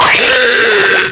CREATDIE.WAV